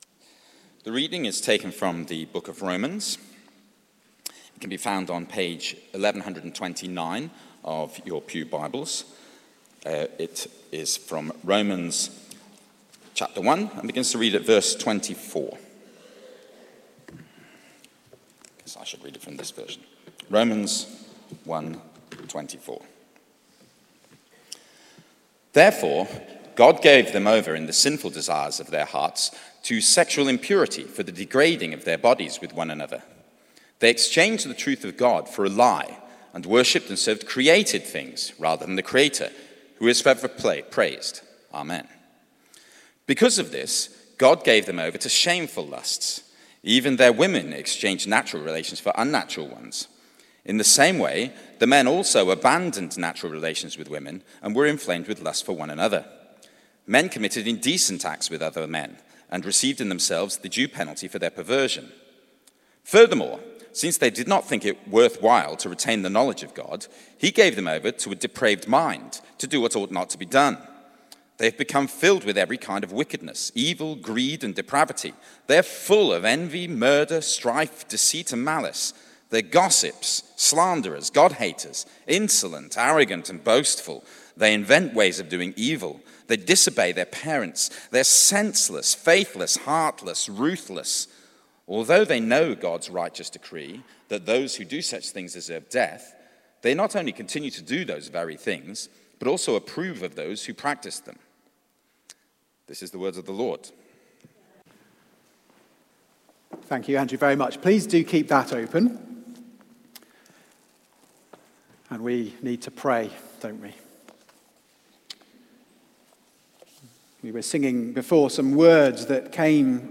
Series: Romans: God's Glorious Gospel! Theme: The World handed over to the consequences of sin Sermon Search: